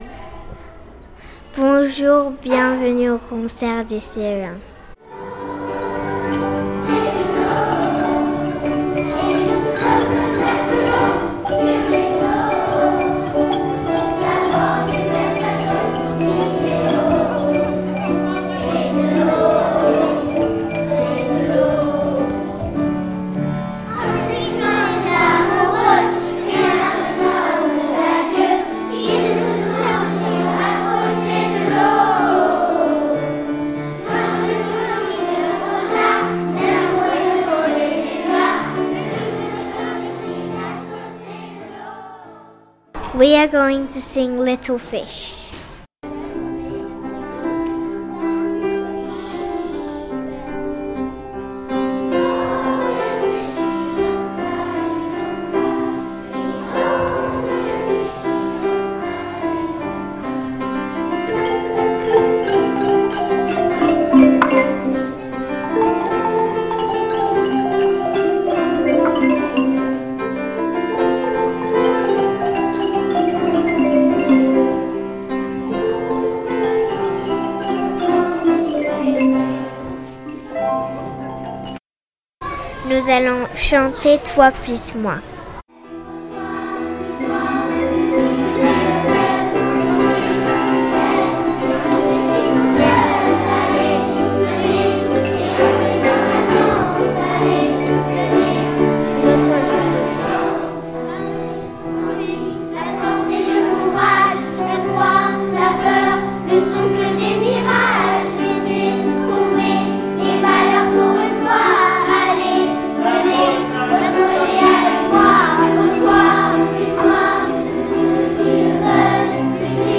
Making-of et live concert: les CE1.
Les Ce1 vous emmènent également en balade en mer, écoutez leurs jolies voix et leurs performances instrumentales.
The Ce1 classes also take you on a sea trip, listen to their pretty voices and their instrumental performances.